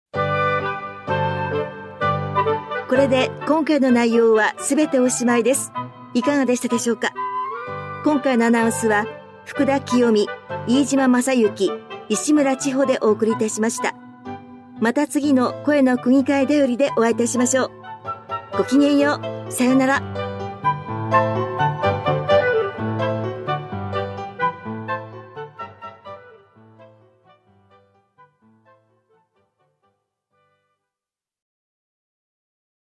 音声データは「きたくぎかいだより」の記事を音声化しています。